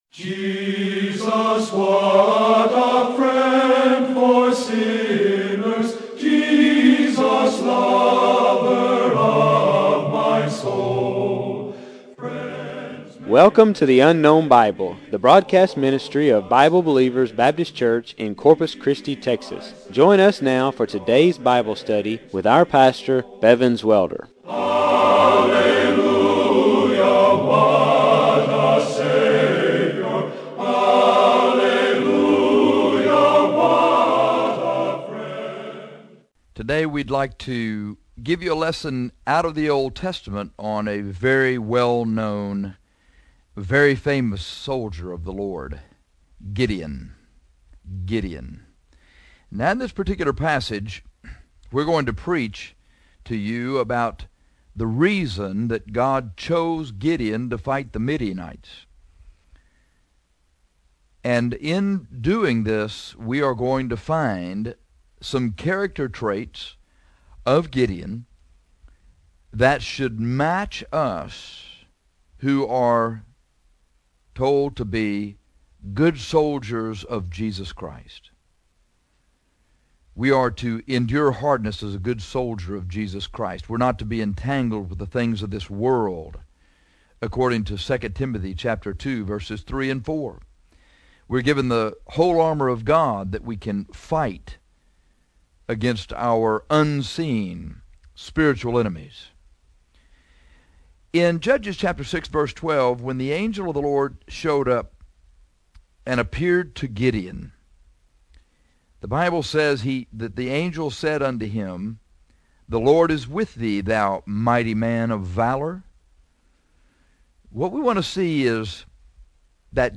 This broadcast on Gideon a Good Soldier reveals why Gideon is the perfect example of the soldier God want us to be.